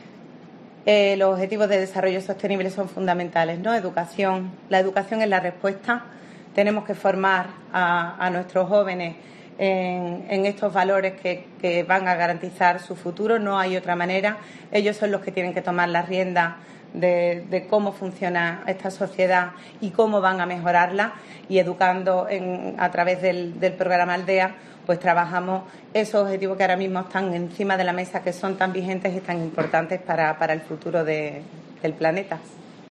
Estela Villalba, delegada de Educación